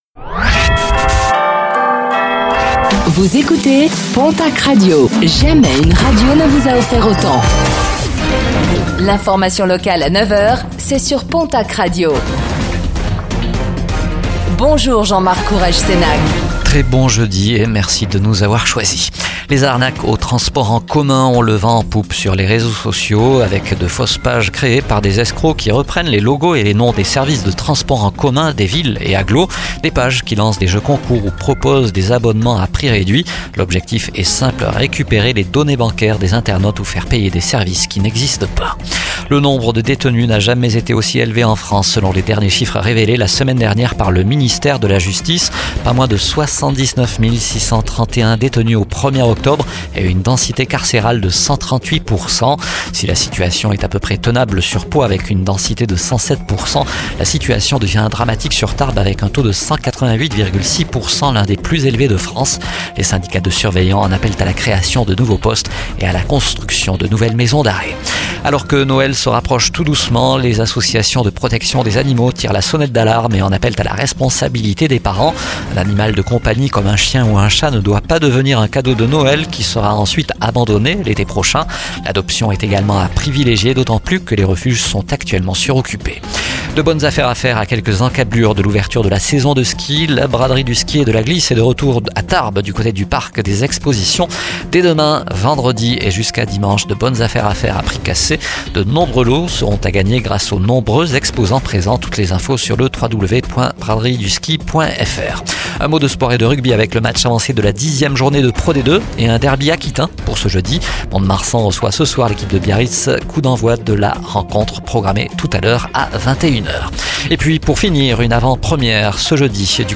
Réécoutez le flash d'information locale de ce jeudi 07 novembre 2024